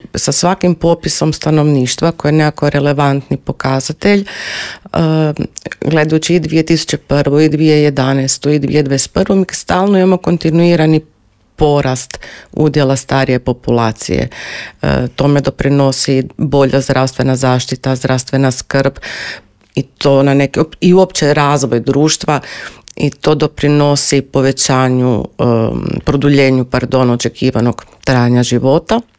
Gostujući u Intervjuu Media servisa objasnila je da je gerontologija znanstvena disciplina koja u svom fokusu ima osobe starije životne dobi.